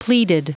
Prononciation du mot pleaded en anglais (fichier audio)
Vous êtes ici : Cours d'anglais > Outils | Audio/Vidéo > Lire un mot à haute voix > Lire le mot pleaded